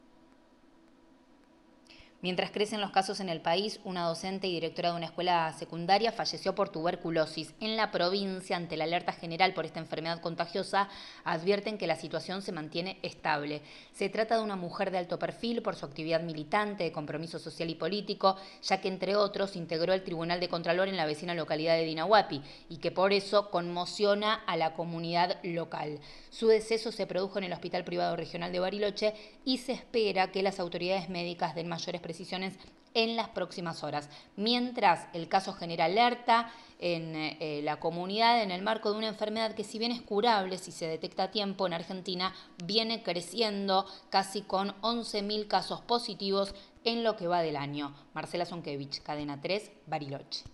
Murió una docente por tuberculosis en Bariloche: hay alerta por la suba de casos - Boletín informativo - Cadena 3 - Cadena 3 Argentina